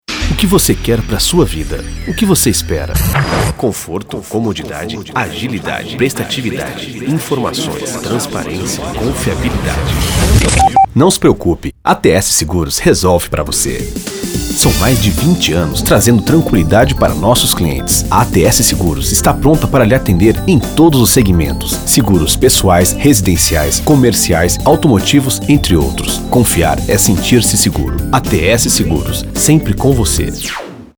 Masculino
Comasa (Volkswagen) - Novo Jetta (Voz Grave)